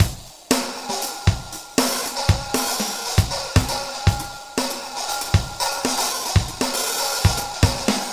Gliss 2fer 6 Drumz.wav